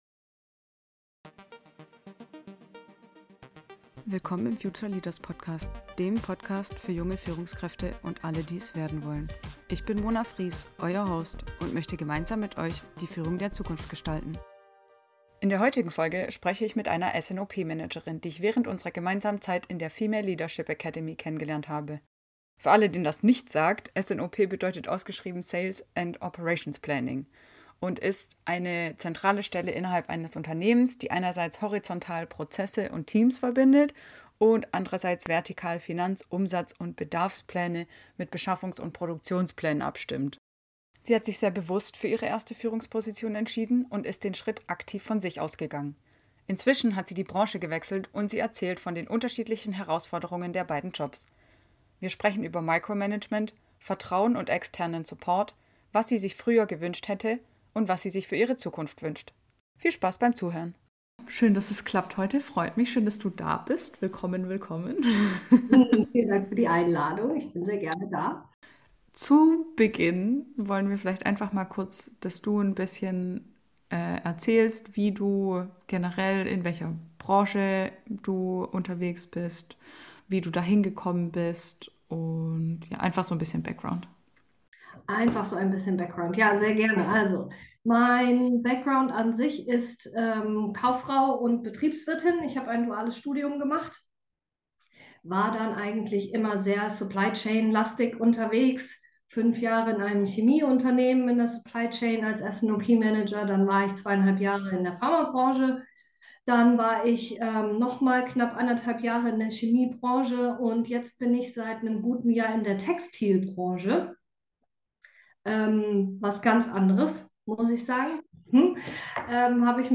Im Interview mit der S&OP (Sales and Operations Planning) Managerin, die schon in Chemie- Pharma- und Textilindustrie gearbeitet hat, geht es um die verschiedenen Herausforderungen, die unterschiedliche Teams und eine schlechte Arbeitskultur mit sich bringen können, was gute Vorbereitung aus...